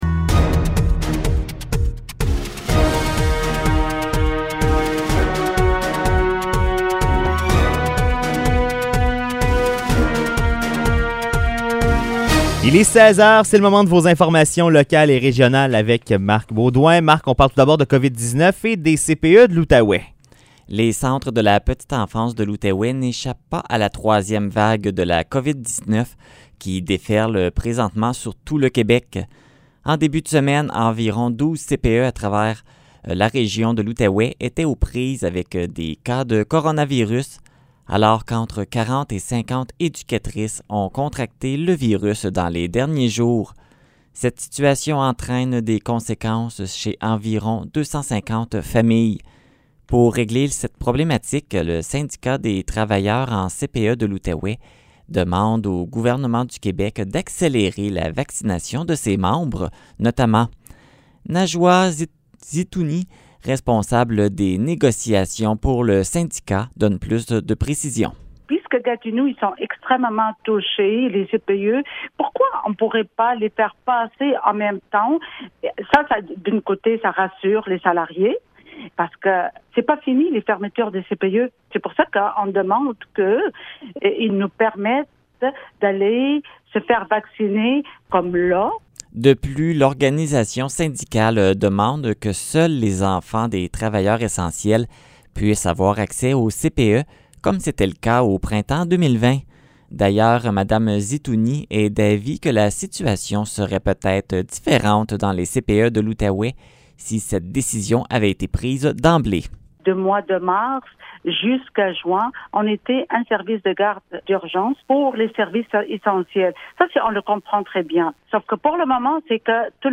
Nouvelles locales - 14 avril 2021 - 16 h